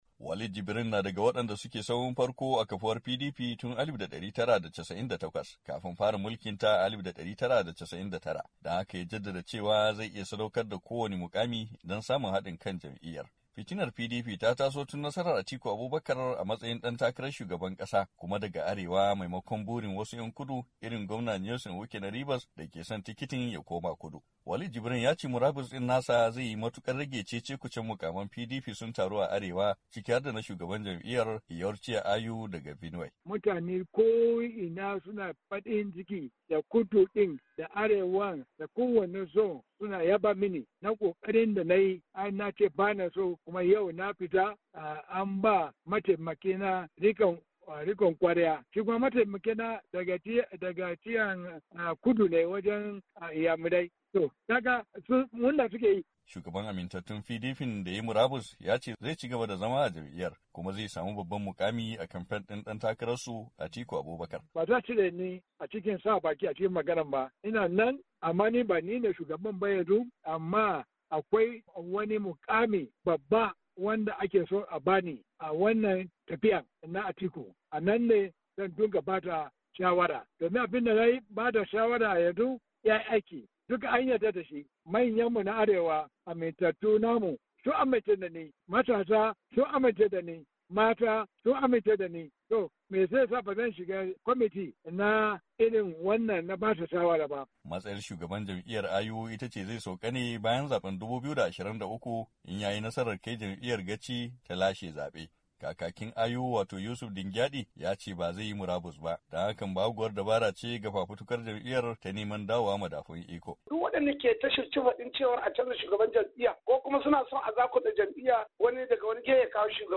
ABUJA, NAJERIYA —